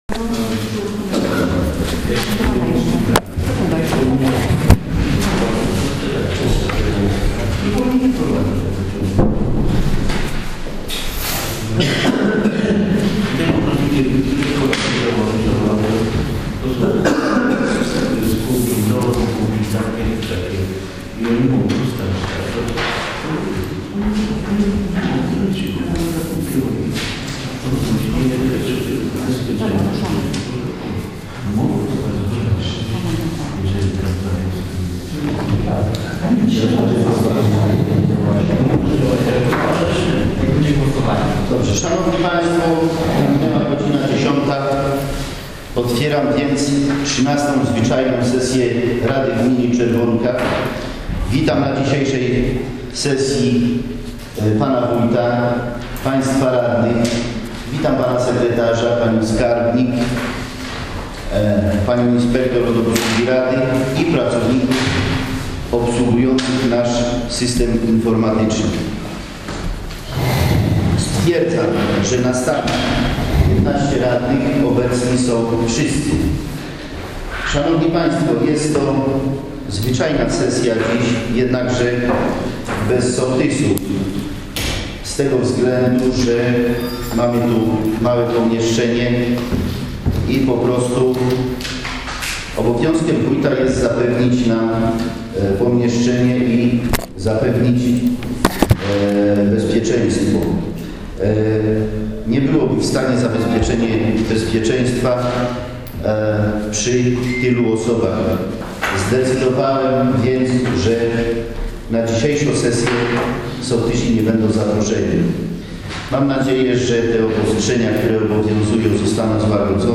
XIII Posiedzenie Rady Gminy Czerwonka - nagranie głosowe